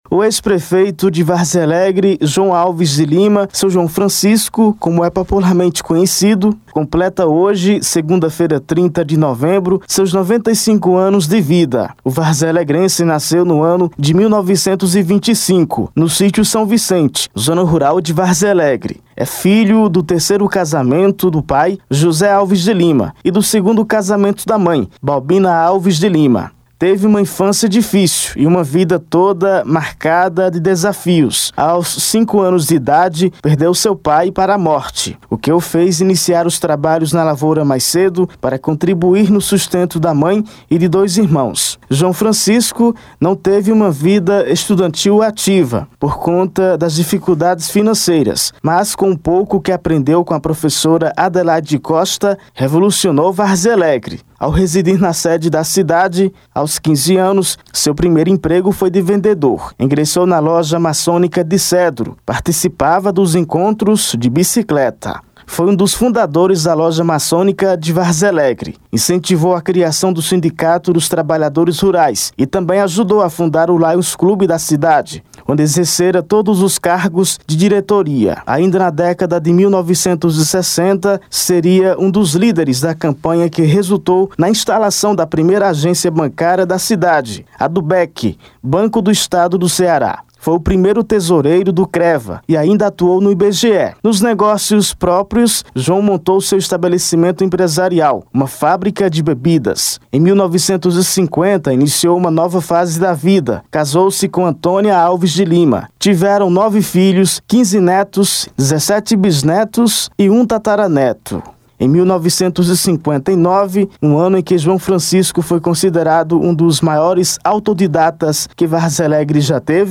Confira no áudio da reportagem: